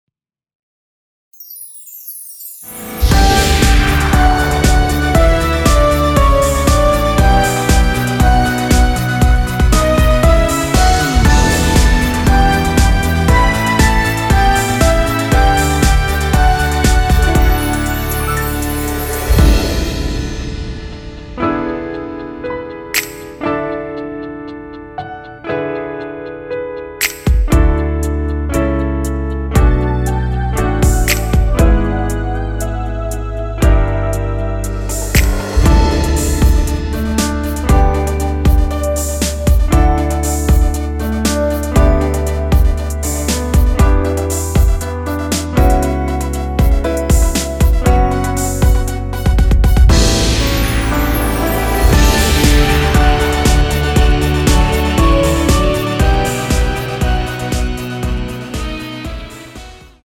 원키에서(-1)내린 MR입니다.
D
앞부분30초, 뒷부분30초씩 편집해서 올려 드리고 있습니다.
중간에 음이 끈어지고 다시 나오는 이유는